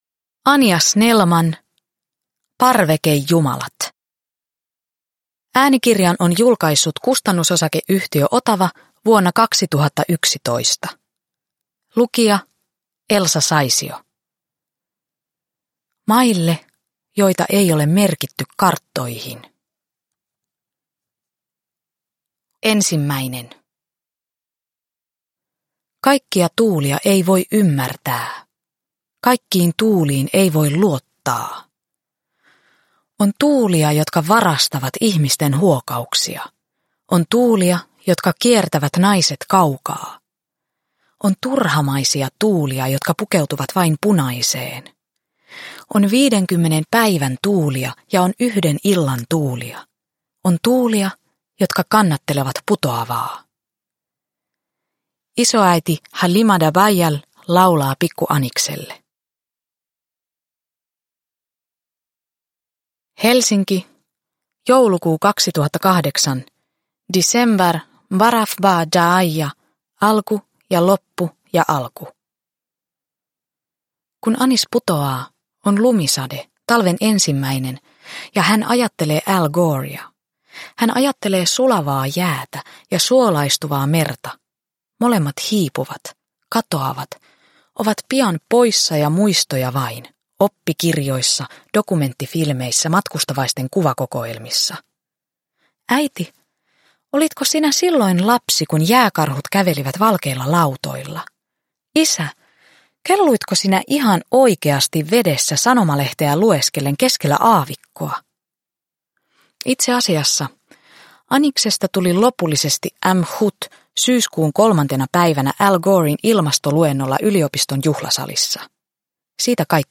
Parvekejumalat – Ljudbok – Laddas ner